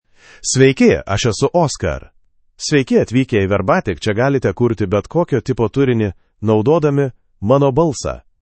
OscarMale Lithuanian AI voice
Oscar is a male AI voice for Lithuanian (Lithuania).
Voice sample
Male
Oscar delivers clear pronunciation with authentic Lithuania Lithuanian intonation, making your content sound professionally produced.